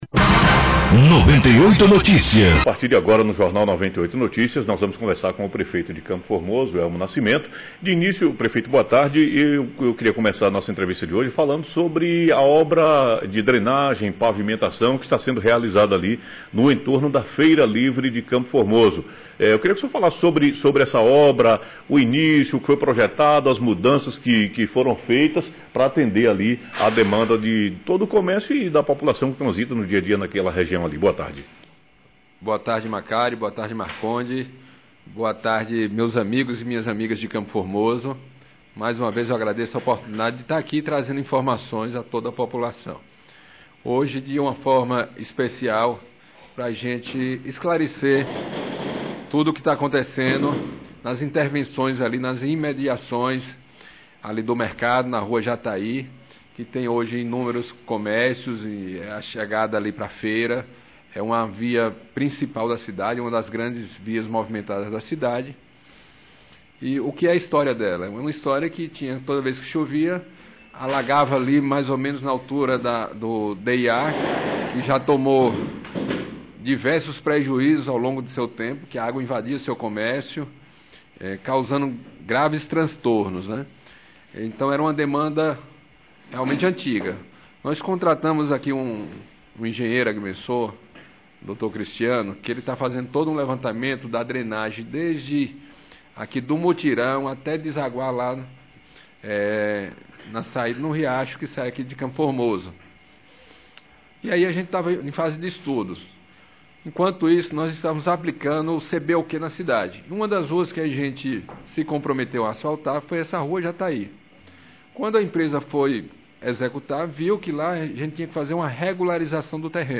Entrevista ao vivo com o Prefeito do município de Campo formoso Elmo Nascimento